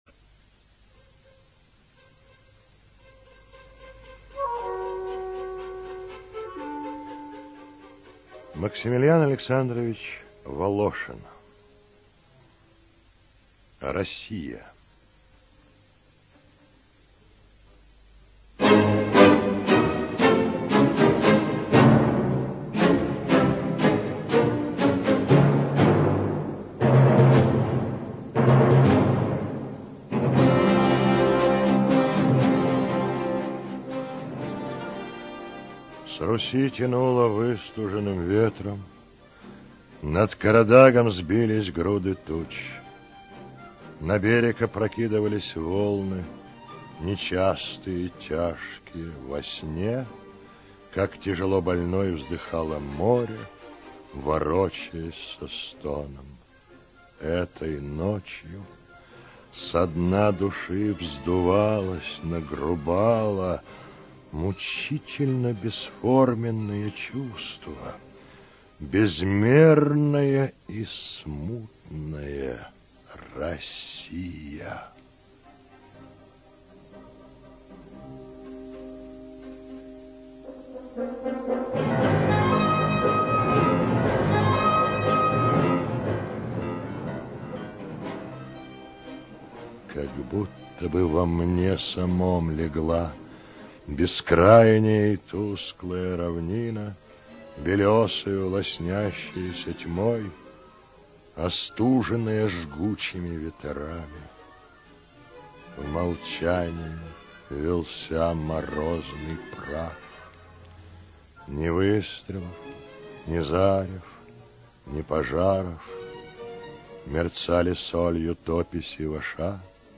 ЧитаетСуховерко Р.
ЖанрПоэзия